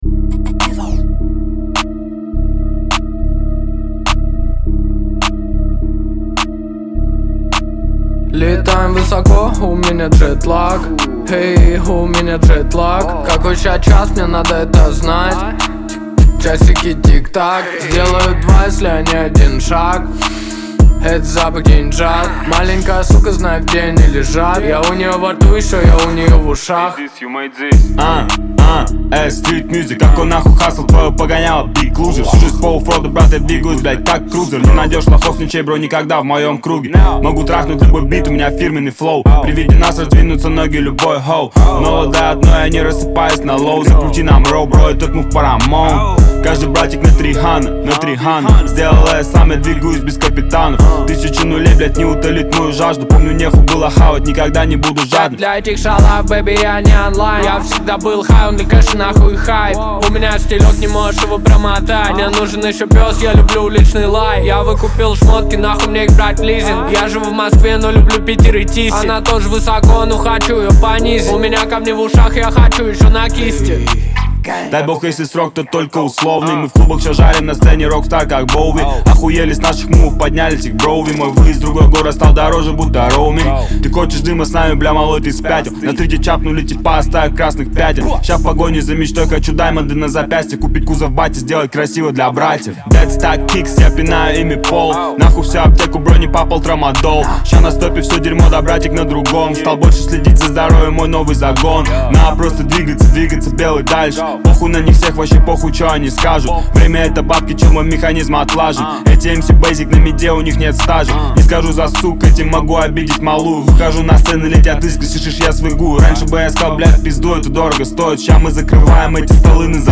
Трек размещён в разделе Русские песни / Эстрада.